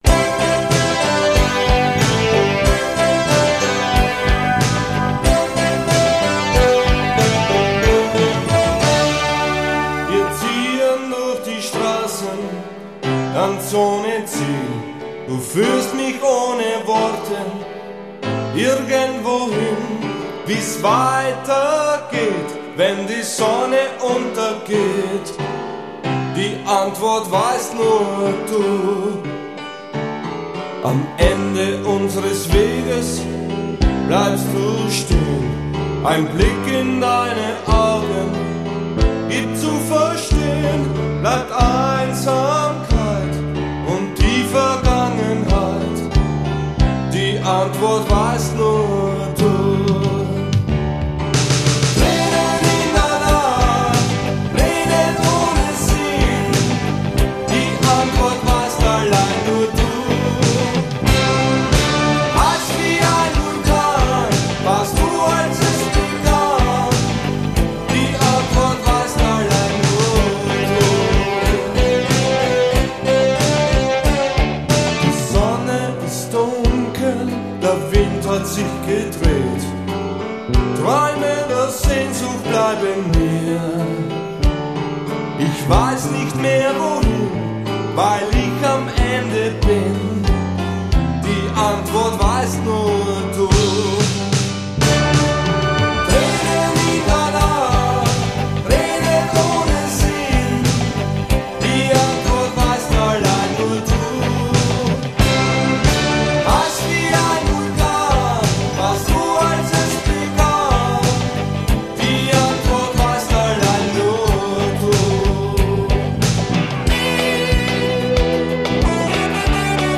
vocals, guitar, keyboards
bass  -  1985 bis 1987